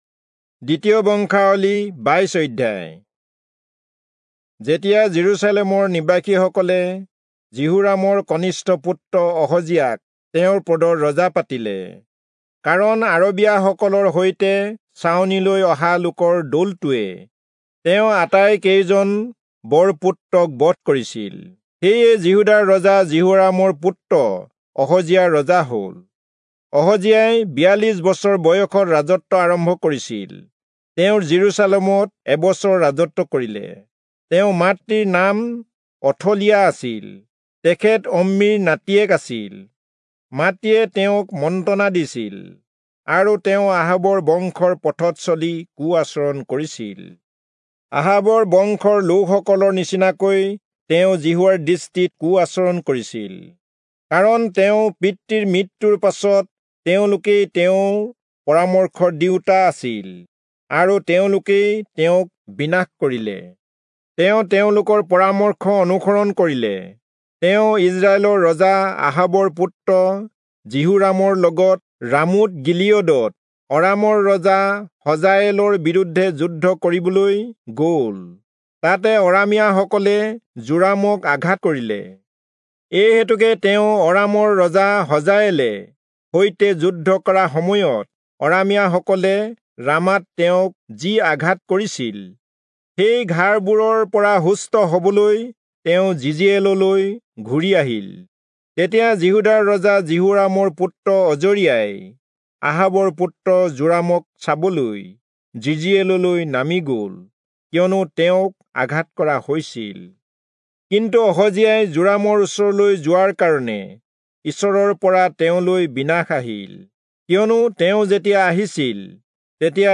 Assamese Audio Bible - 2-Chronicles 23 in Irvta bible version